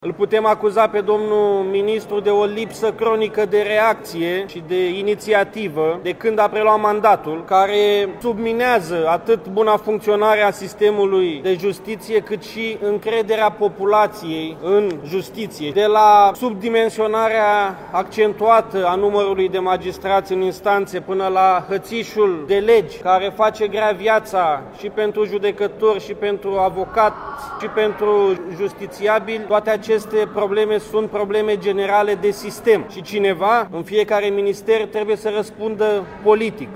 Liderul deputaților AUR, Adrian Enache: „Îl putem acuza pe domnul ministru de o lipsă cronică de reacție și de inițiativă de când a preluat mandatul”